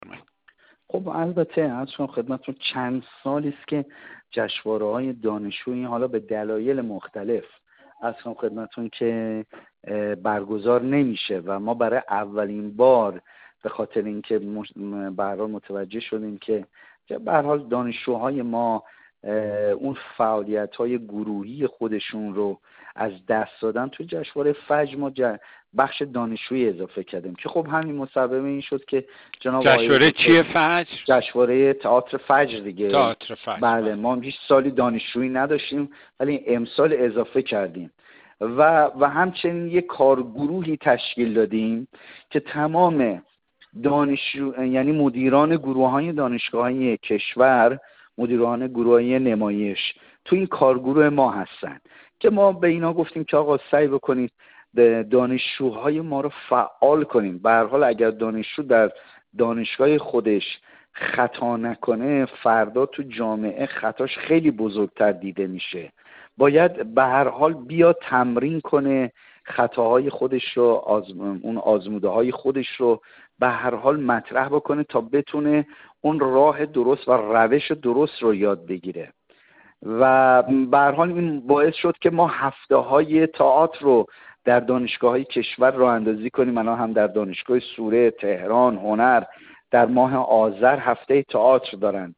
پیرامون این موضوع با کاظم نظری عضو هیئت علمی دانشگاه سوره و مدیرکل هنرهای نمایشی وزارت فرهنگ و ارشاد اسلامی گفت‌‌وگویی انجام داده‌ایم.